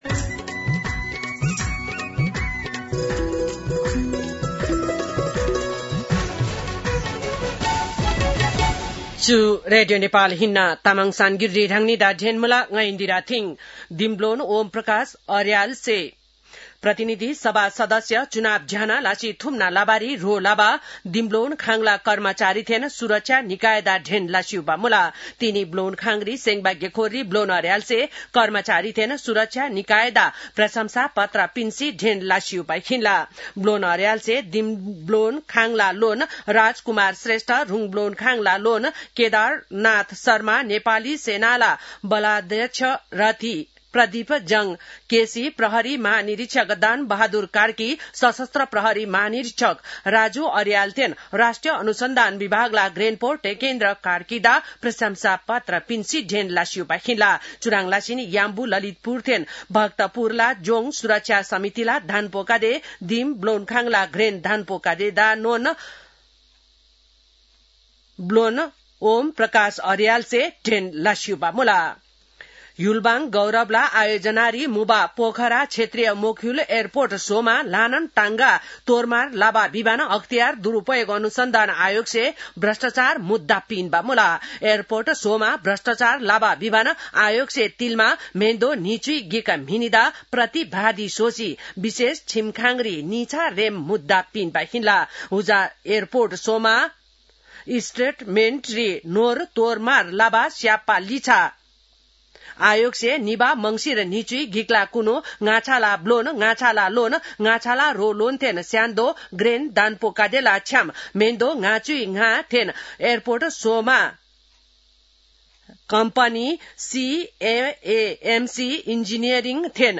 तामाङ भाषाको समाचार : ९ चैत , २०८२